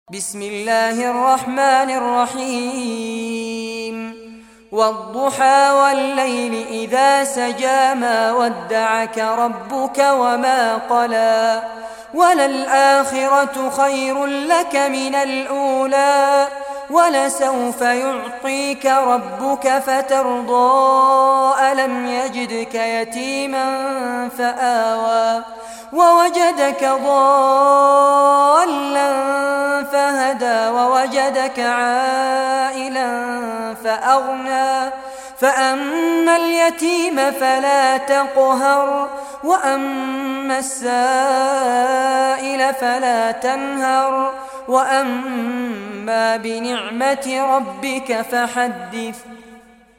Surah Ad-Duha Recitation by Fares Abbad
Surah Ad-Duha, listen or play online mp3 tilawat / recitation in Arabic in the beautiful voice of Sheikh Fares Abbad.
93-surah-duha.mp3